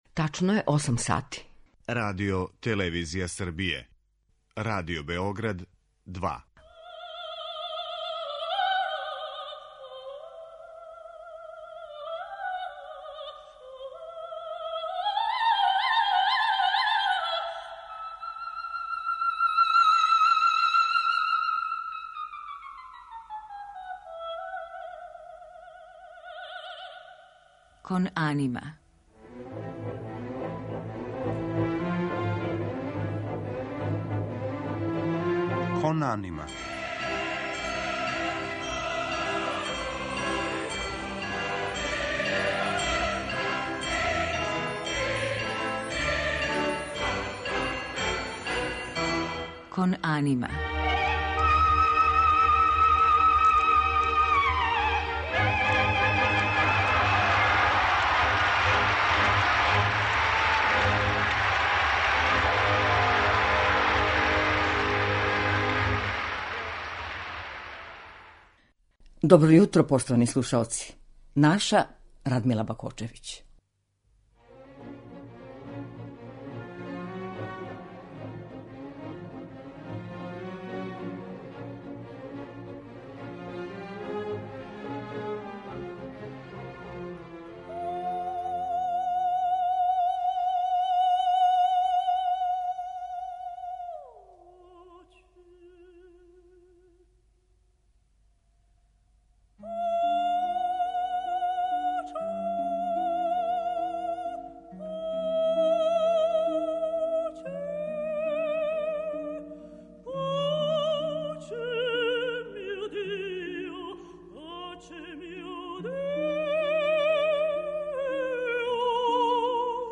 Гошћа у емисији: Радмила Бакочевић
У музичком делу биће емитоване арије из Вердијевих опера "Моћ судбине", "Трубадур" и "Набуко", "Кнез од Семберије", Петра Коњовића, Пучинијеве "Тоске" и Белинијеве "Норме", у њеном извођењу.